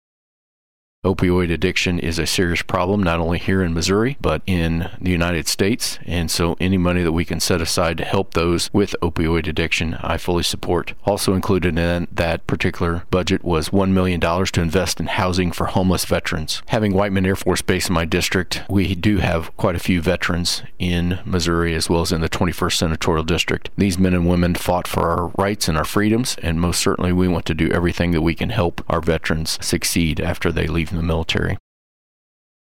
2. Senator Hoskins adds there are other items for rural Missouri as well.